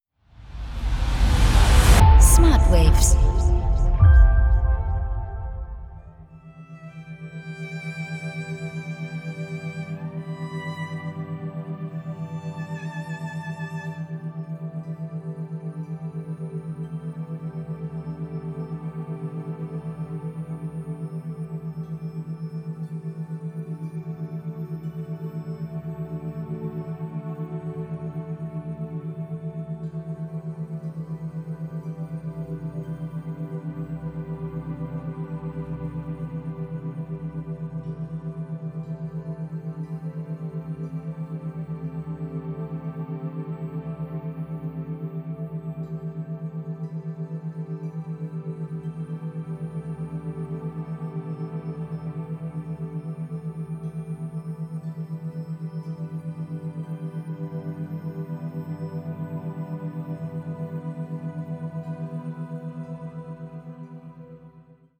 • Methode: Isochrone Beats
• Frequenz: 7,83 Hertz